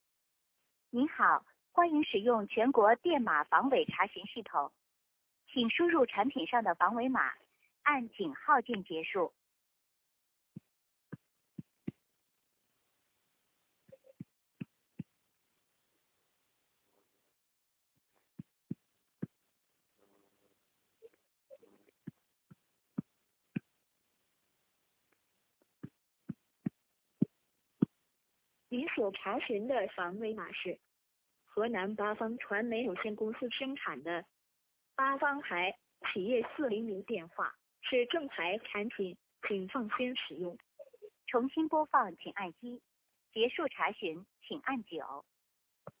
电话防伪查询录音